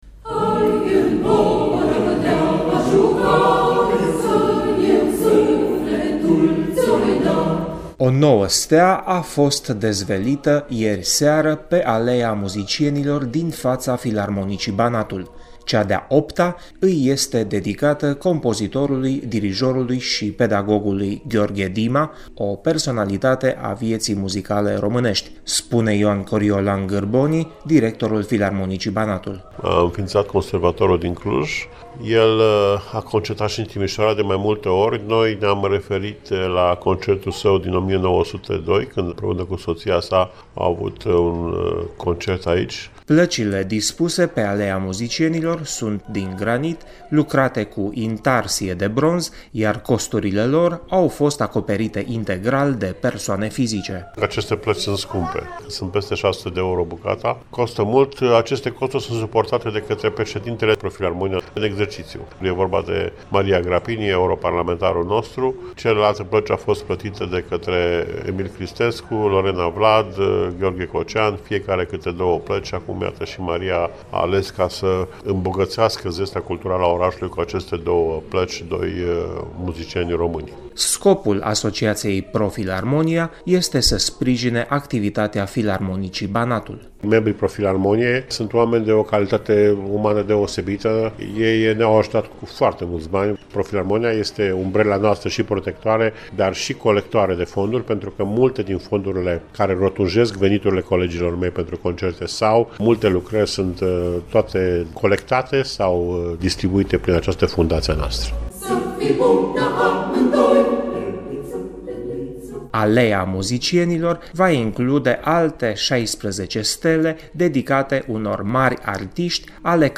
Aleea Muzicienilor din Timişoara are o nouă stea. Joi seara a fost dezvelită steaua lui Gheorghe Dima, compozitor, dirijor şi pedagog.